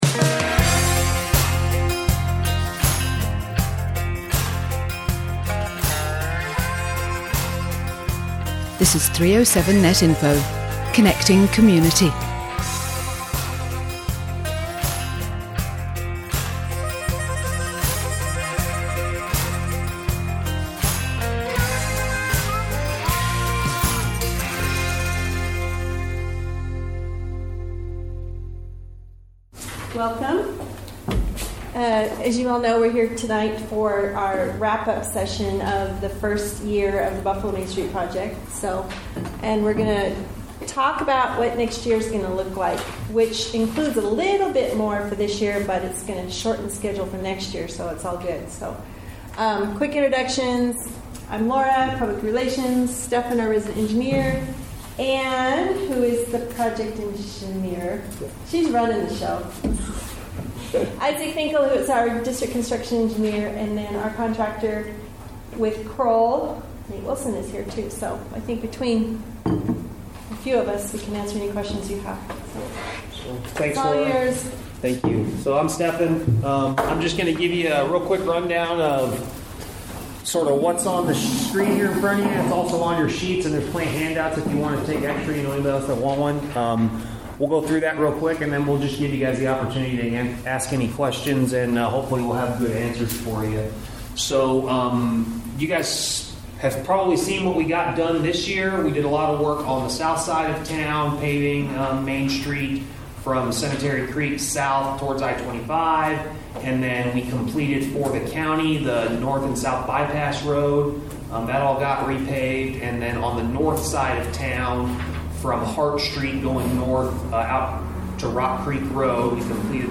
Listen to WYDOT Public Update Meeting June 11th, 2024